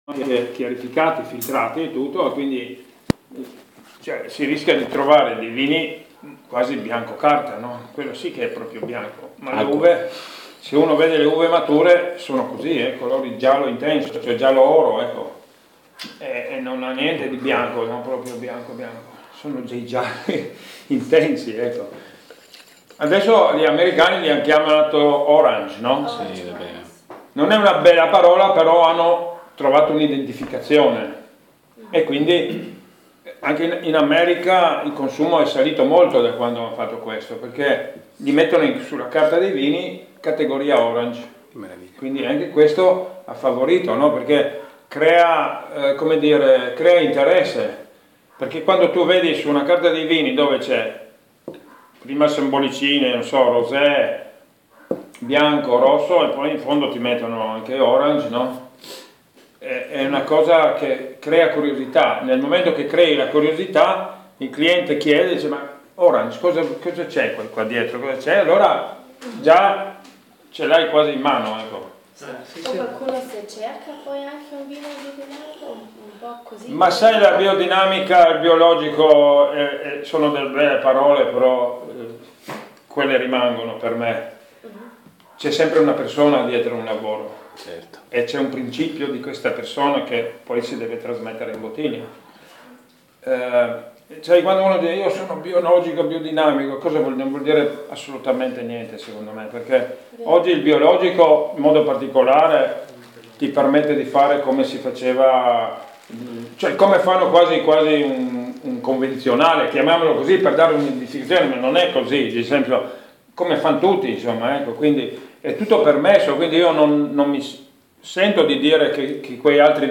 Ha una voce pacata e sicura